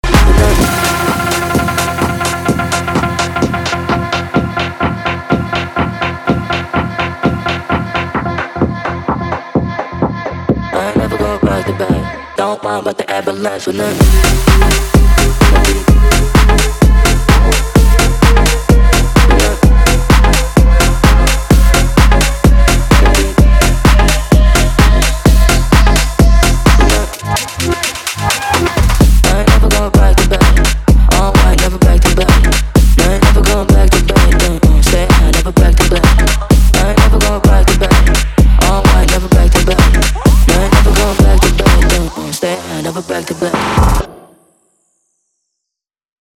• Качество: 320, Stereo
громкие
мощные
EDM
Bass House
Раздел: Клубная музыка. House. Trance. Electro